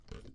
描述：滴水滴滴